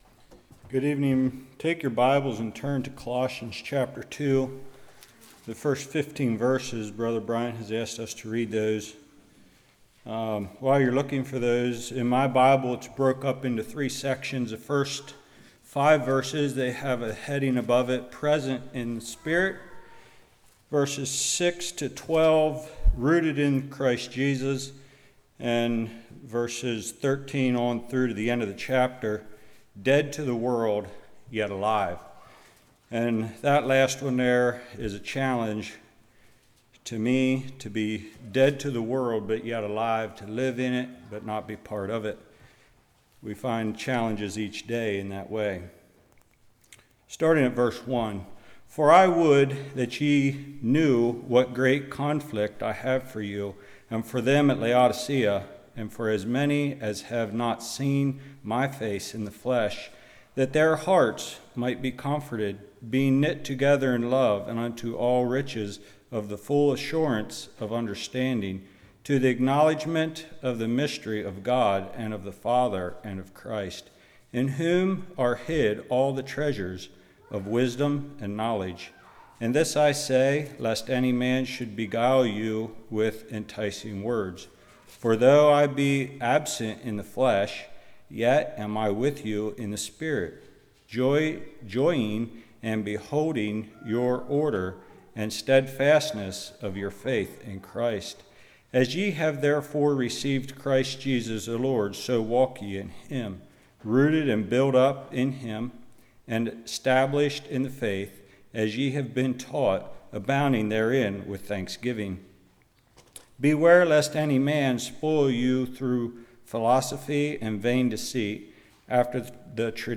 Colossians 2:1-15 Service Type: Evening Ye Are Complete In Christ?